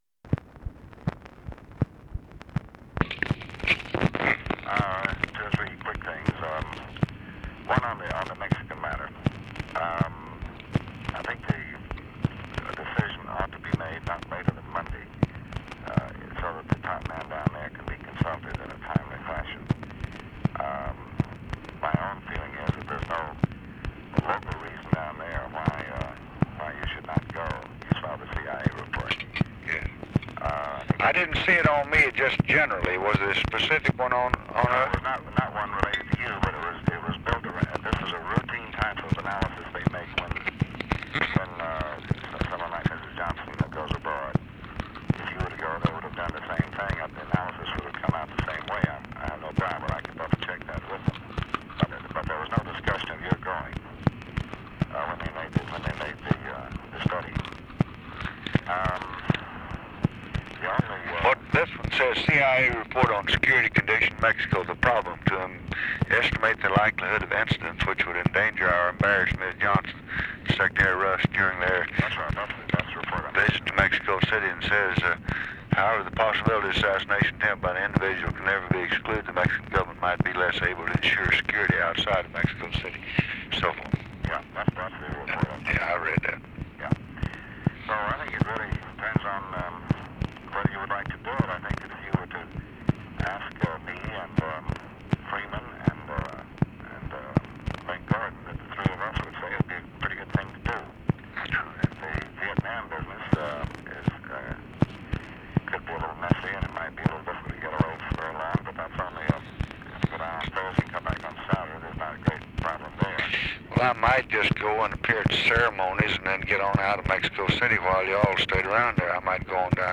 Conversation with DEAN RUSK, April 9, 1966
Secret White House Tapes